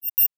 Holographic UI Sounds 28.wav